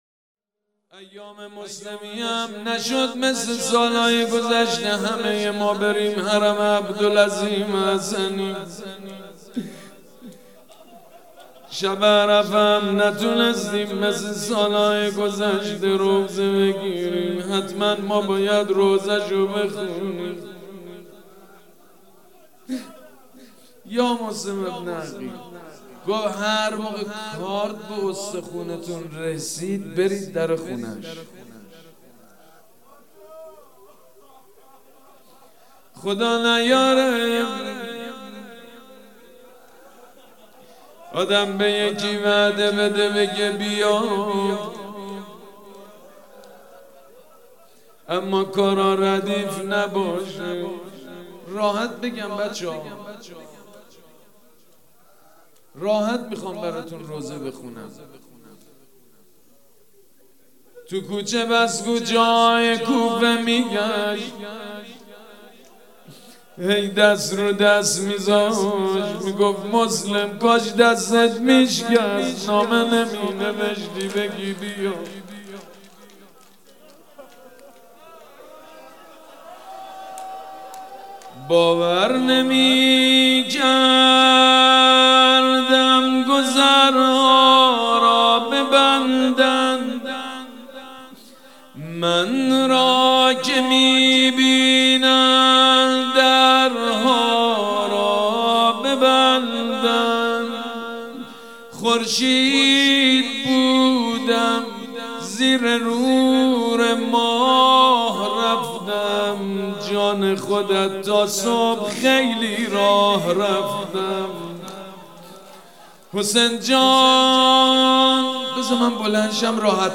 روضه حضرت مسلم بن عقیل سید مجید بنی فاطمه
مداحی جدید حاج سید مجید بنی فاطمه شب اول محرم 1399 هیات ریحانة الحسین(س)